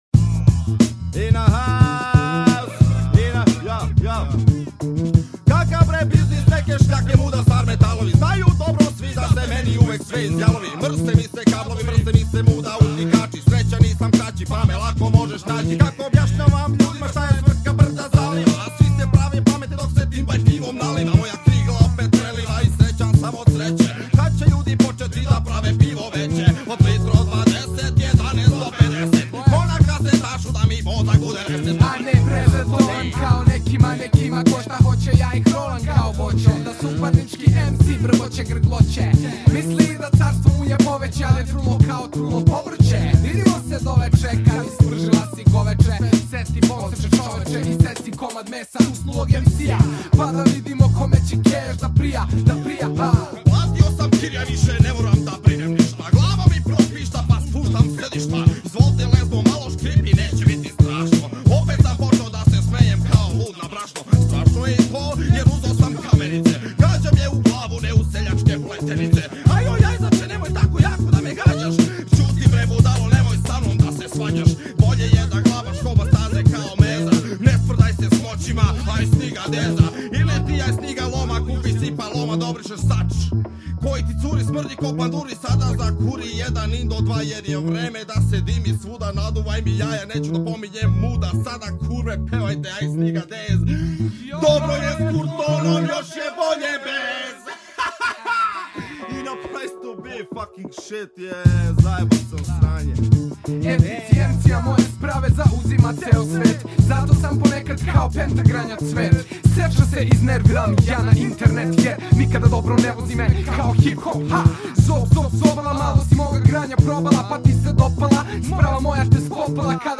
preko fanki bas linje.